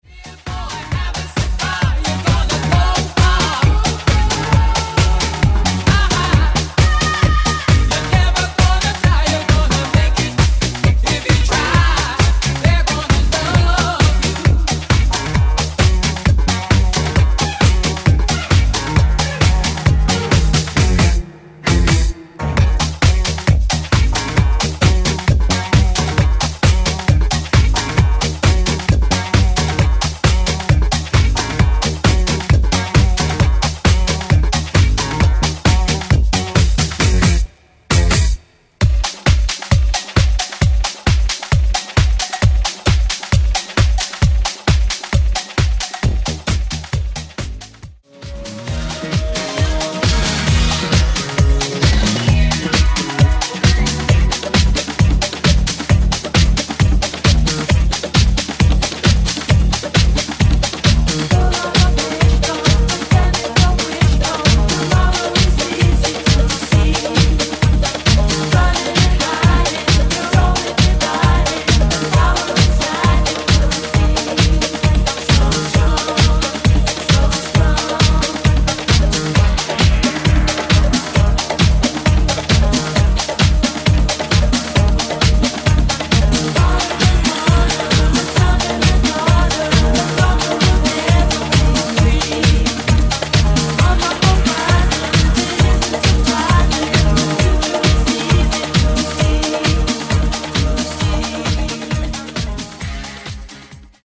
Genre: 70's